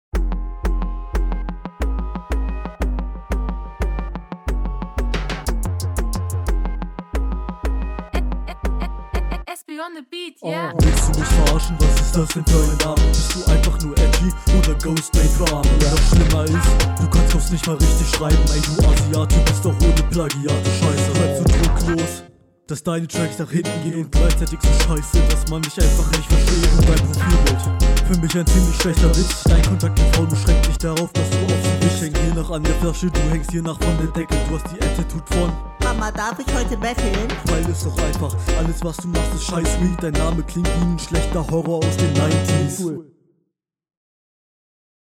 Flow: Der Flow ist nice und delivery geht ein bisschen unter weil die vocal halt …
Flow: Du bist zwar zu großen Teilen auf dem Takt, jedoch sind die Betonungen und …